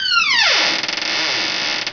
Door Squeak Download
doorsqueak.mp3